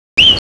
A Scream of Alarm Calls
Vancouver Island marmot
ascending, descending, flat,